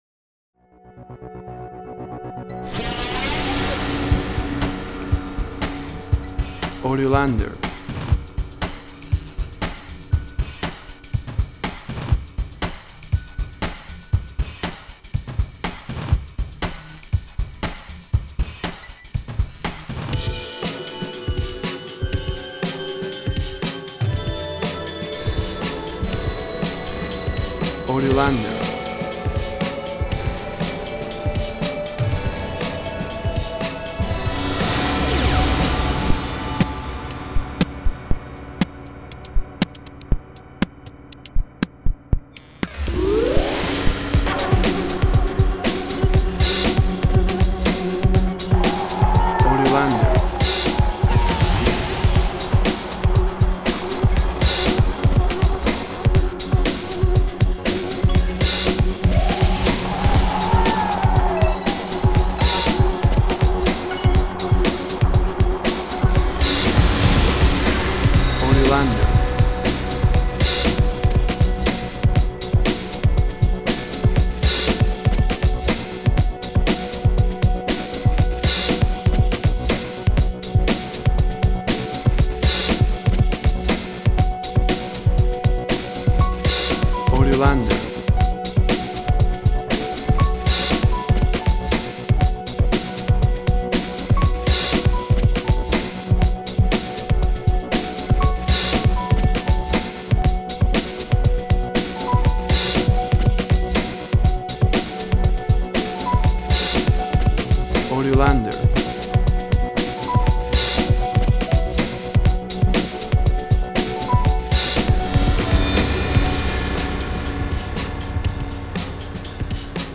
Electronic musical fragment energetic and futuristic.
Tempo (BPM) 100